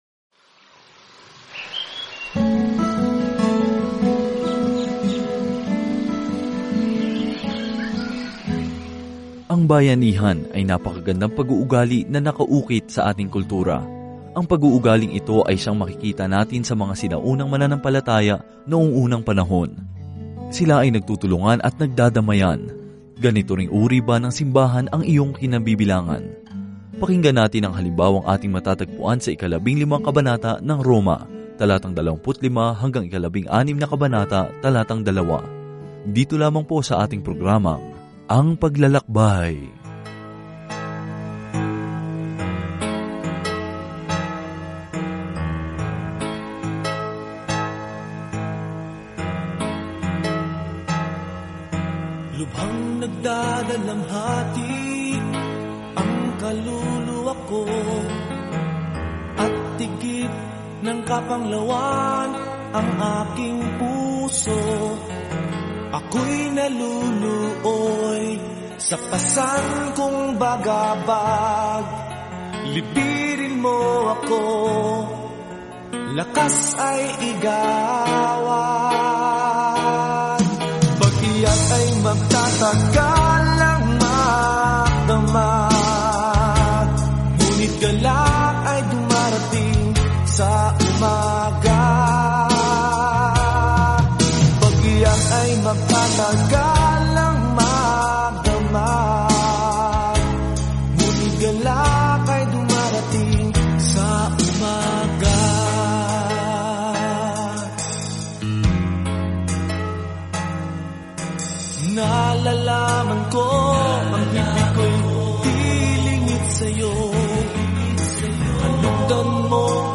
Araw-araw na paglalakbay sa mga Romano habang nakikinig ka sa audio study at nagbabasa ng mga piling talata mula sa salita ng Diyos.